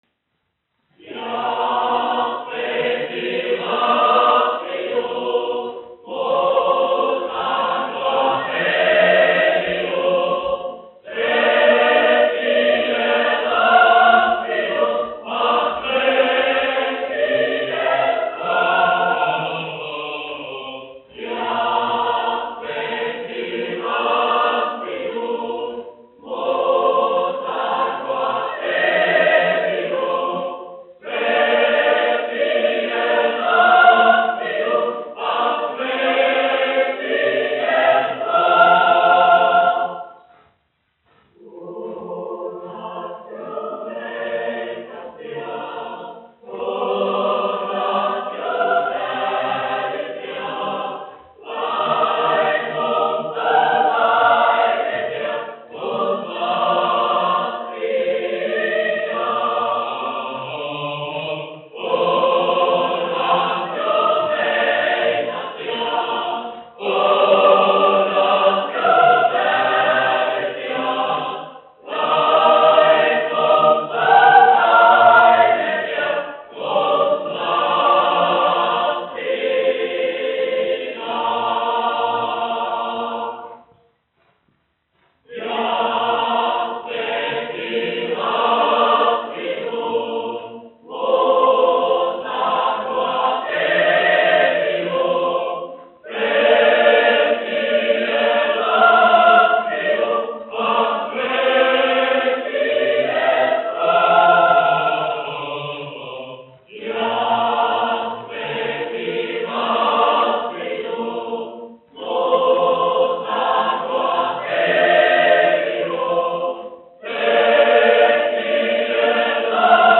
Latvijas Nacionālā opera. Koris, izpildītājs
1 skpl. : analogs, 78 apgr/min, mono ; 25 cm
Nacionālās dziesmas un himnas
Kori (jauktie)
Latvijas vēsturiskie šellaka skaņuplašu ieraksti (Kolekcija)